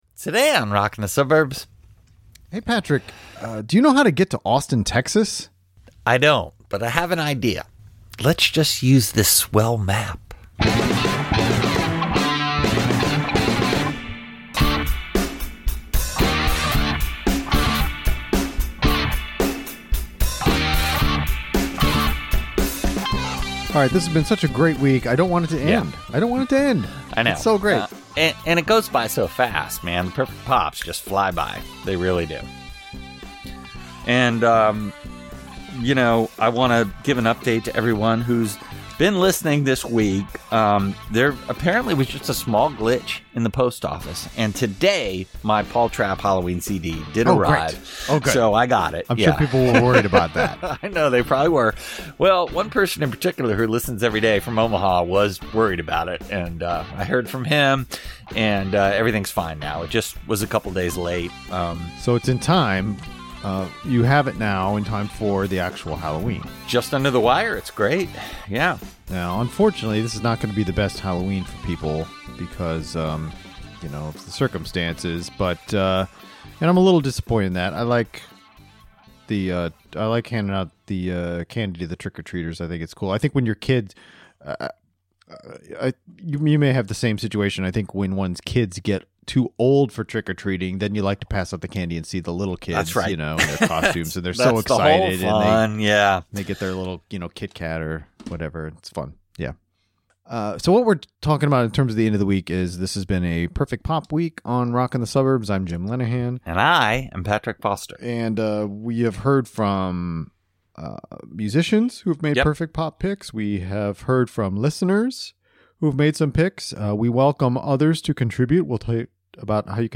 theremin-forward song
classic punk